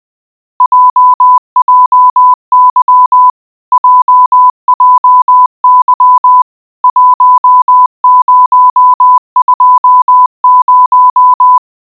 1. コールサイン＆時刻モールス部：モールス信号によるコールサイン「JJY」2回＆モールス信号による時刻（時2桁、分2桁）1回
• 信号周波数：1000Hz
• WPM(Words per minute)：20WPM
コールサイン＆時刻モールス部は次のコマンドで生成しました。例として「10時20分」の場合を示します。